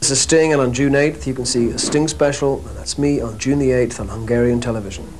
WAV FILES FROM BUDAPEST
WELCOME.WAV: (8 bit mono, 24.5 kHz, 5 s, 125 KB) Sting welcomes the Hungarian viewers